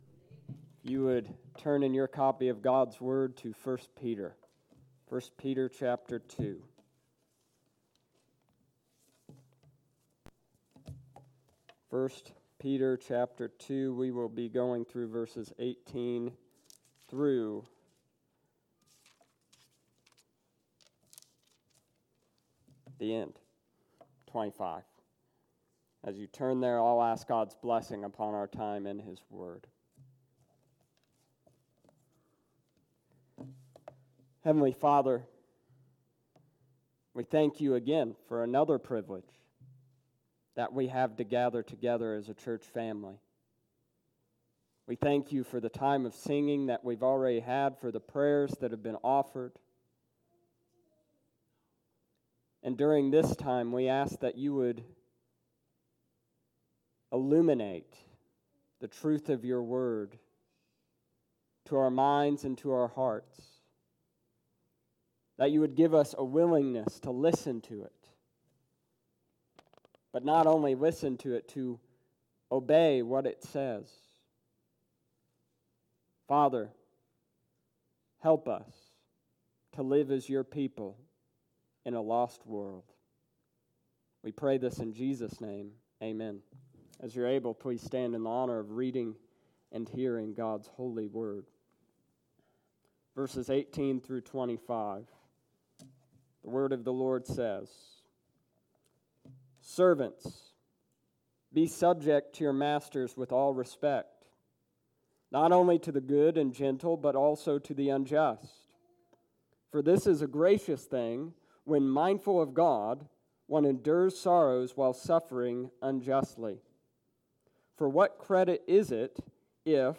Godliness at Work June 16th,2019 Sunday Evening